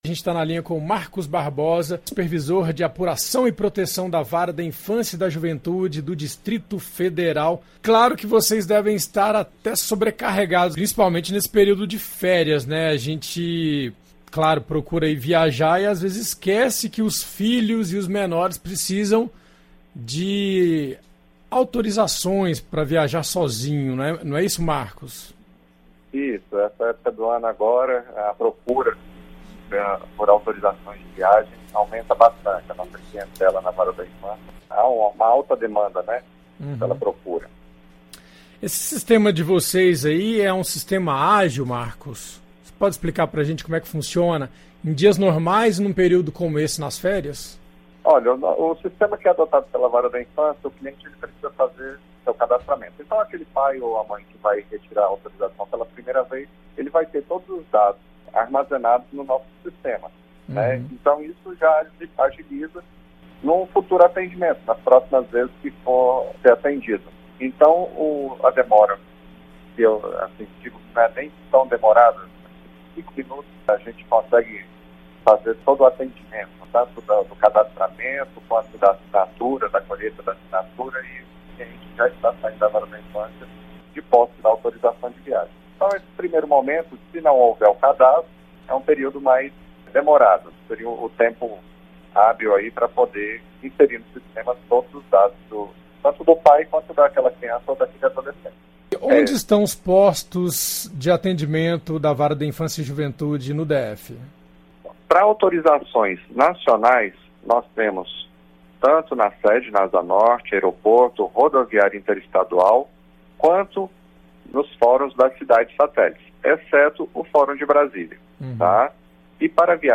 Entrevista: Saiba como obter autorização para viagem de menores de idade